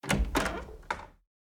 Door Open 1.ogg